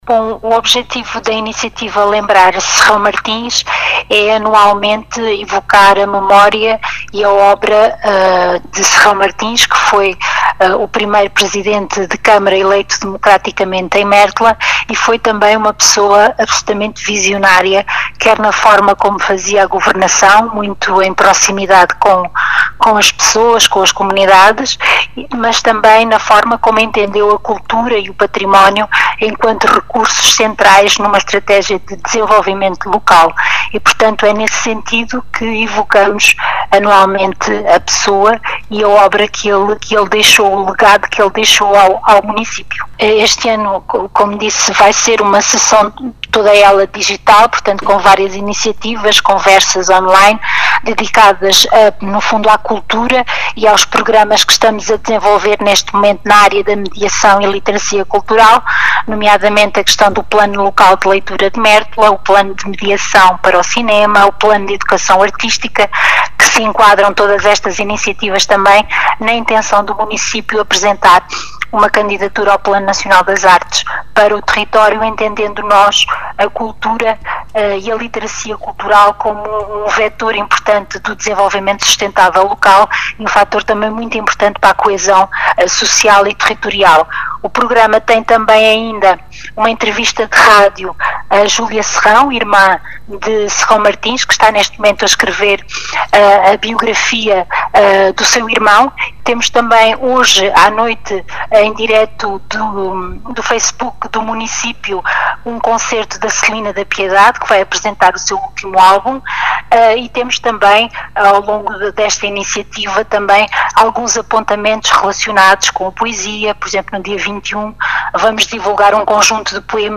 As explicações são de Rosinda Pimenta, vereadora da Câmara Municipal de Mértola, que refere como objetivo da iniciativa relembrar o primeiro autarca eleito em democracia, mas também um “visionário” do poder local.